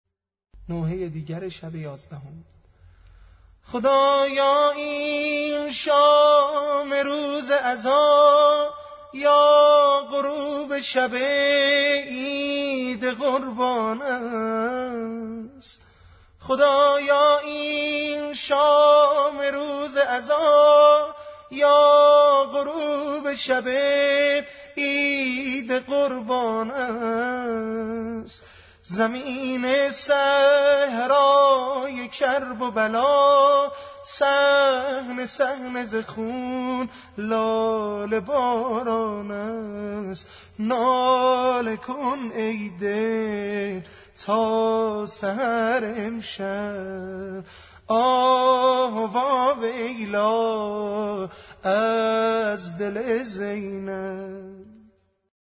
اشعار شام غریبان امام حسین(ع) همراه سبک سنتی - ( خدایا این شام روز عزا یا غروب شب عید قربان است)